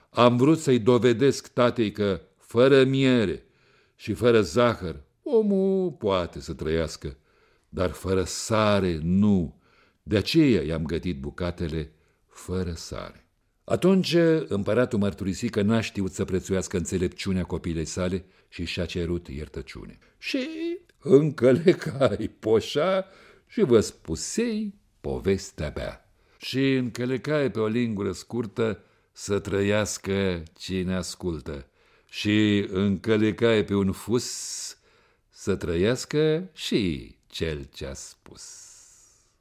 Vocea sa inconfundabilă a putut fi ascultată zilele trecute şi la Radio România: